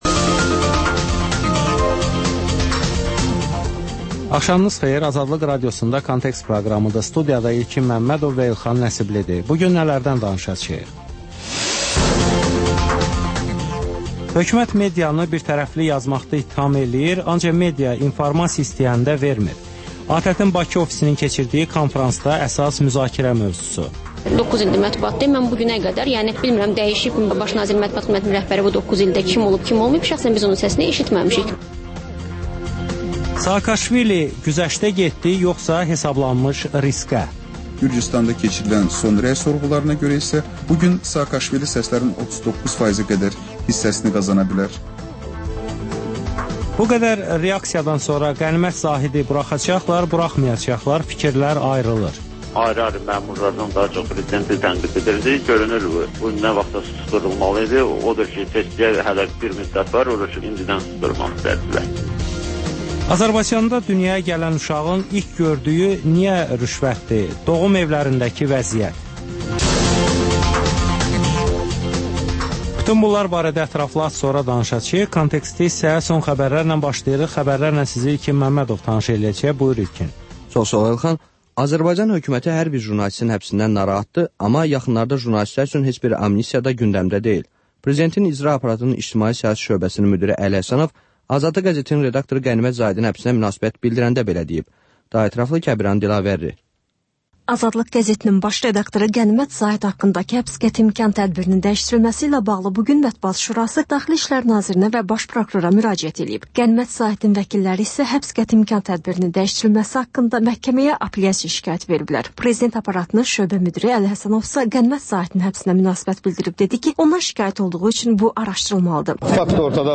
Xəbərlər, müsahibələr, hadisələrin müzakirəsi, təhlillər, daha sonra 14-24: Gənclər üçün xüsusi veriliş